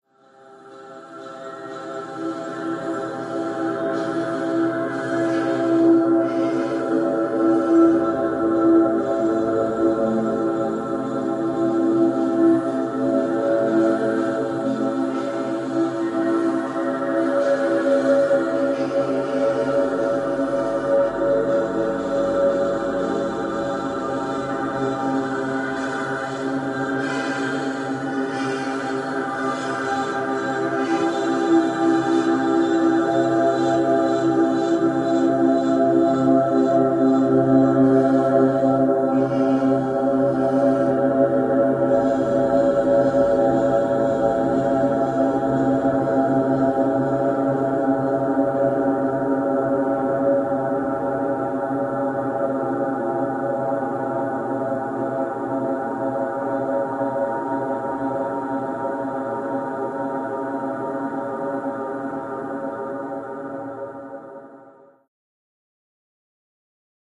Choral
Choral Texture Ambience Choral